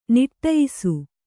♪ niṭṭayisu